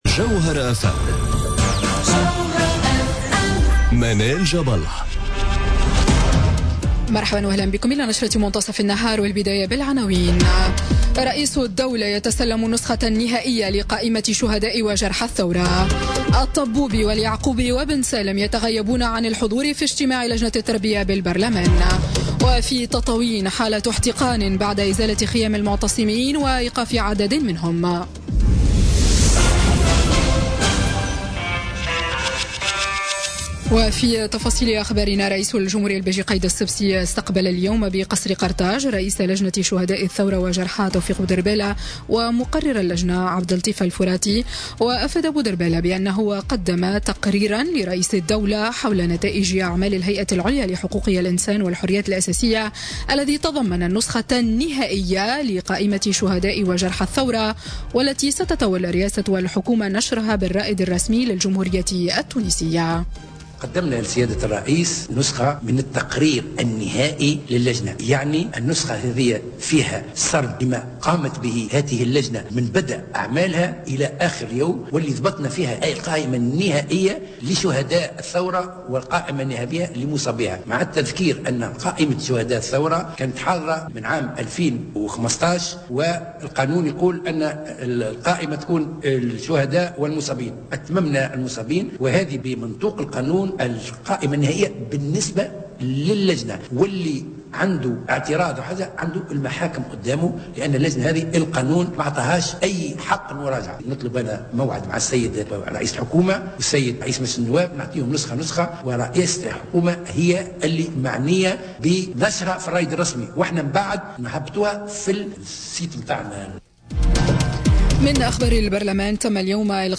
نشرة أخبار منتصف النهار ليوم الإثنين 01 أفريل 2018